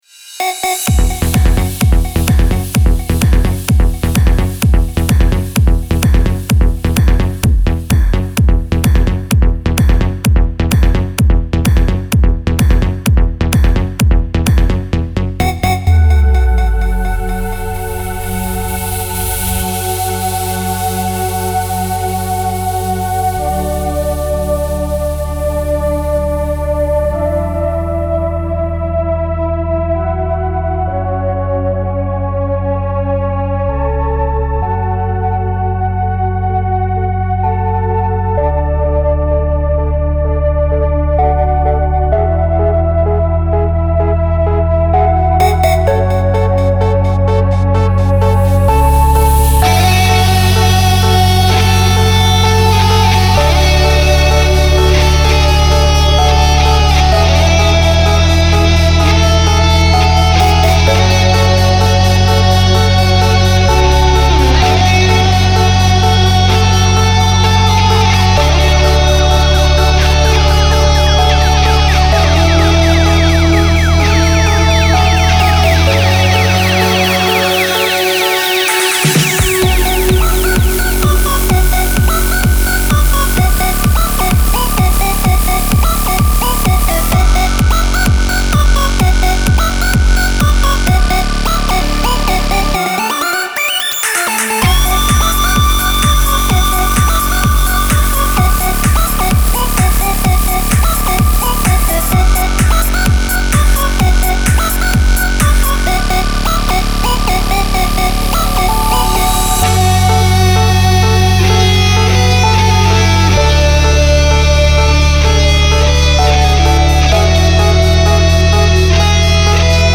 Genre : Electronique